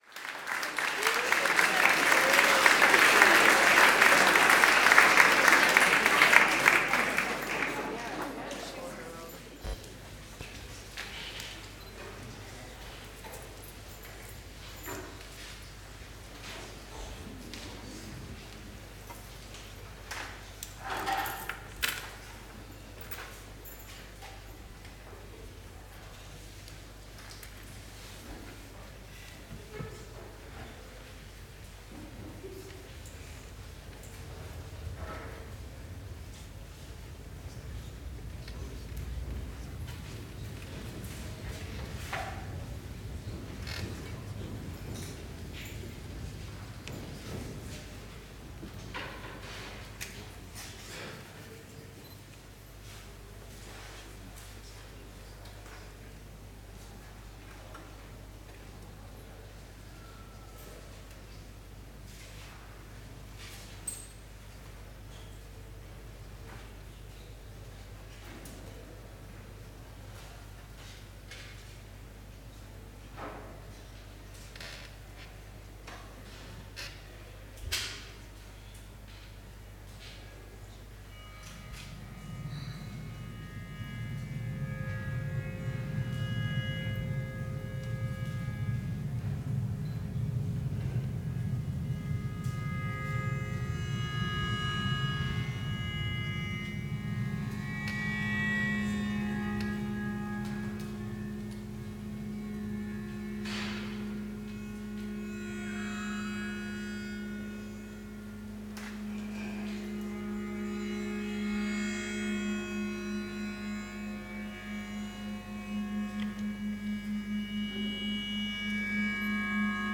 Long Stringed Instrument